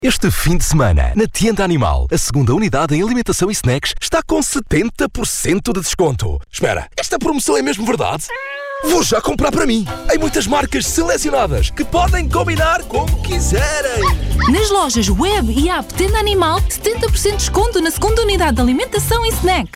The Portuguese voiceover, friendly and impactful!
Commercial character